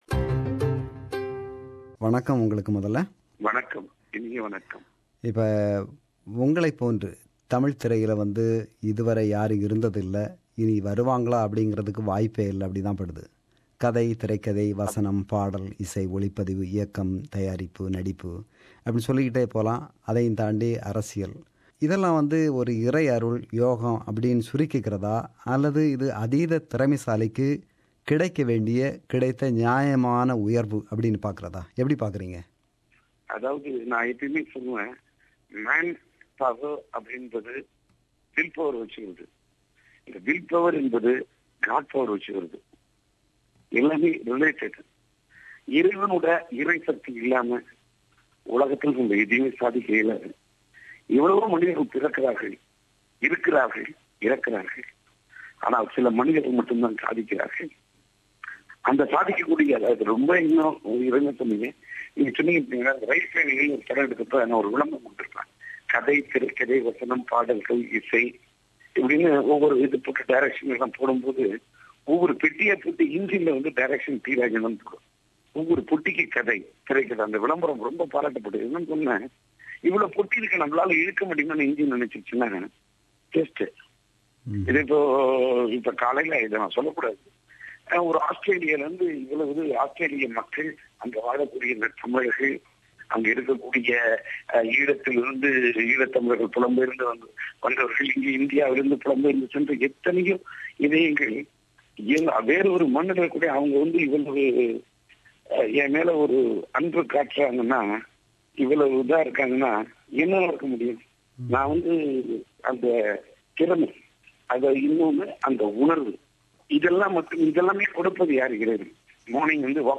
This is an interview with him.